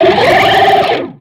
Catégorie:Cri de Boguérisse Catégorie:Cri Pokémon (X et Y)